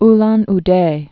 (län--dā, -län-dĕ)